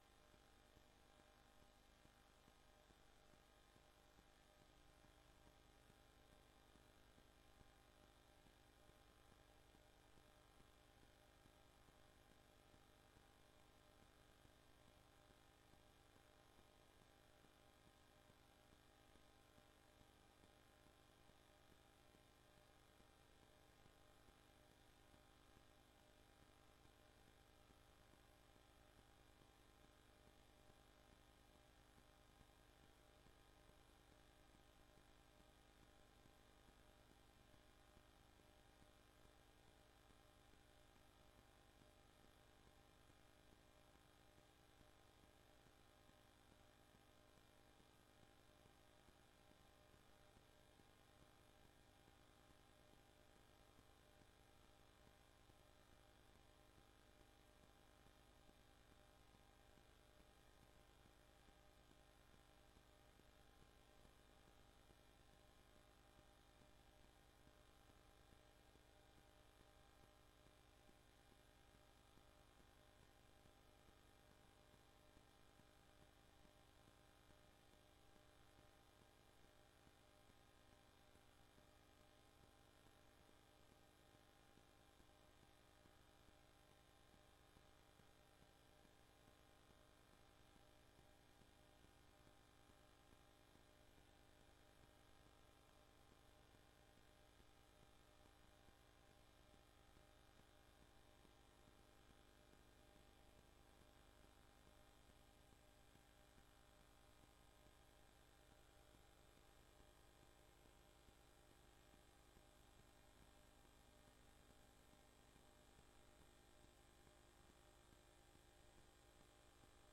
Locatie: Raadzaal
Aftrap door wethouder
Presentatie over geselecteerde maatregelen